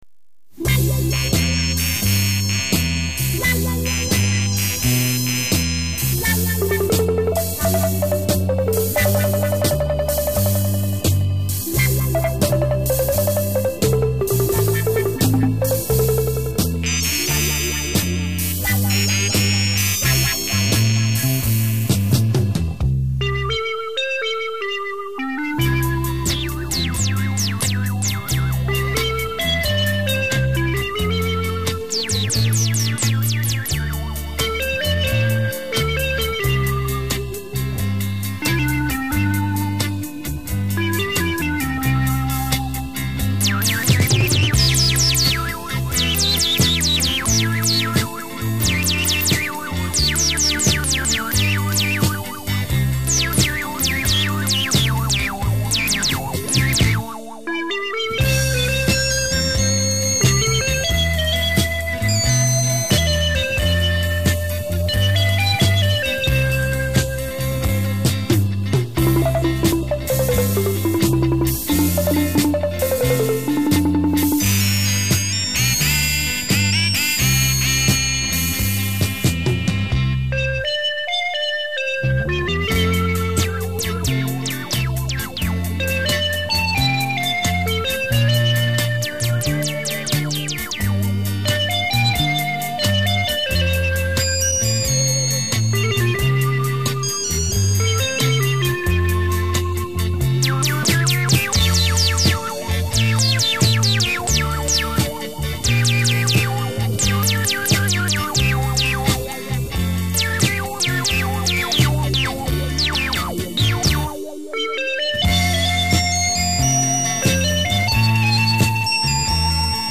资源出处：原版TP唱片录转